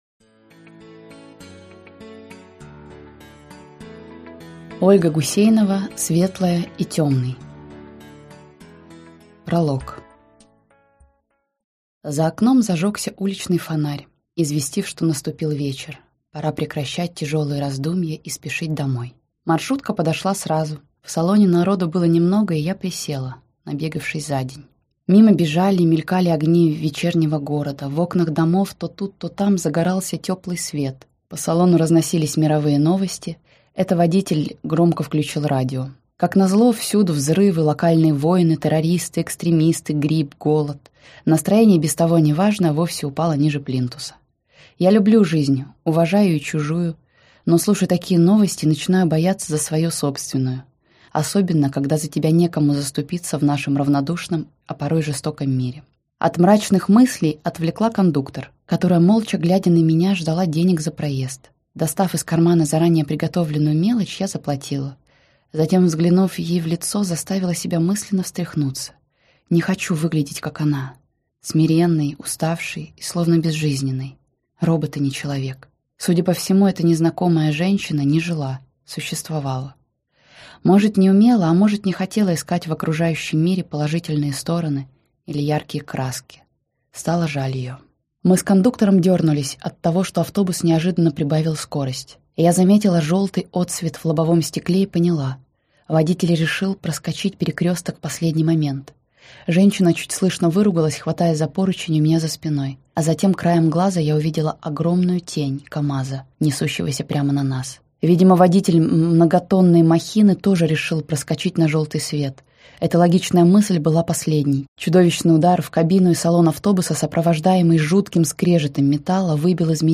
Аудиокнига Светлая и Темный - купить, скачать и слушать онлайн | КнигоПоиск